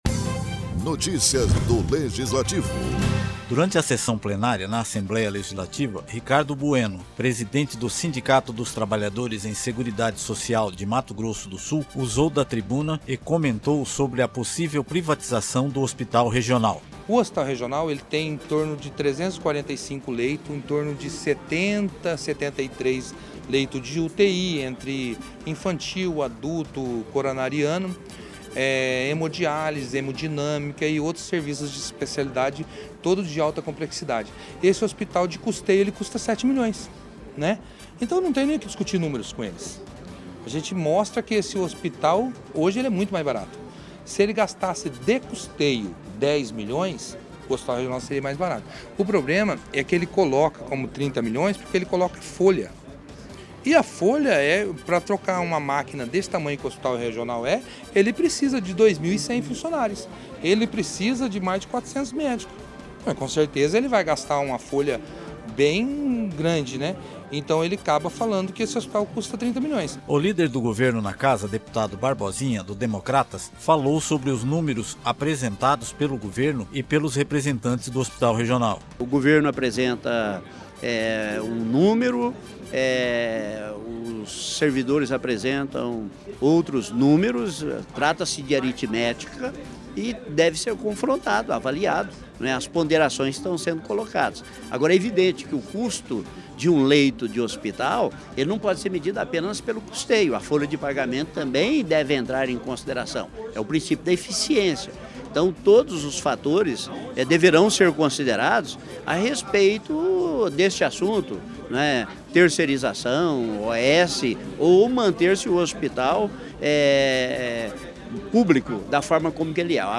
O sindicalista usou a tribuna e pediu intermediação dos deputados estaduais contra suposta privatização do hospital.